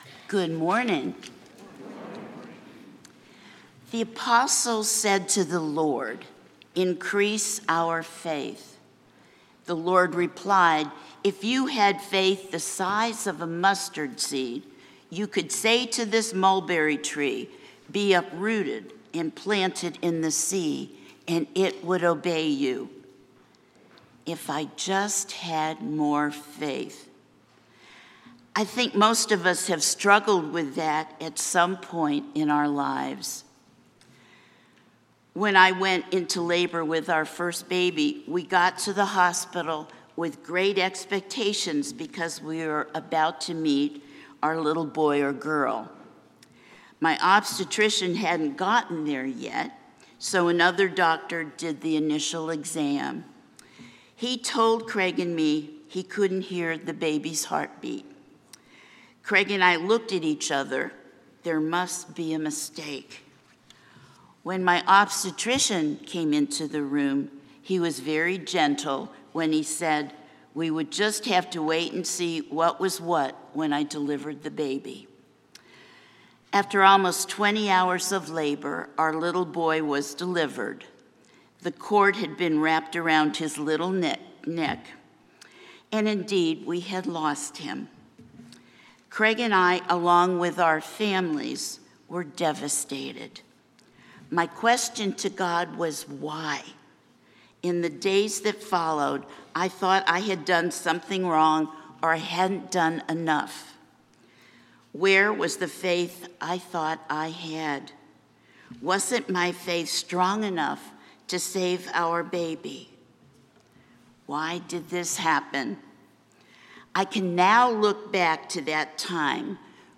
preaches on the seventeenth Sunday after Pentecost.